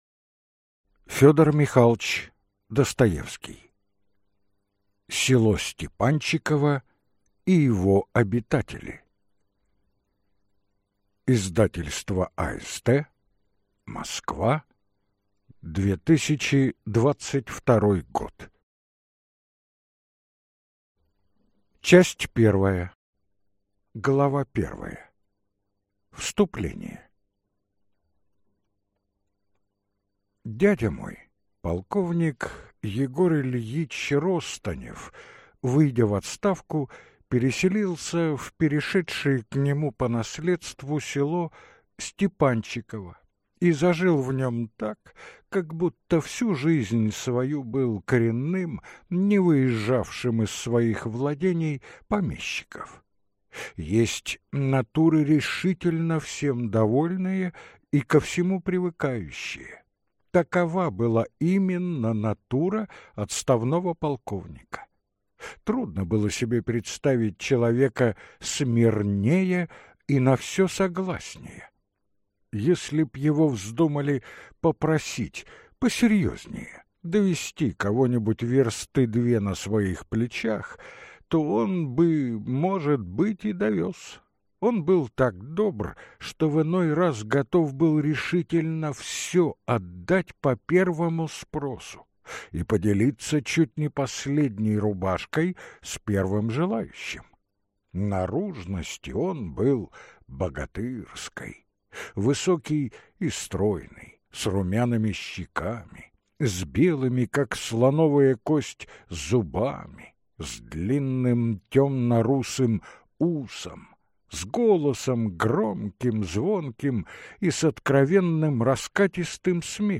Аудиокнига Село Степанчиково и его обитатели | Библиотека аудиокниг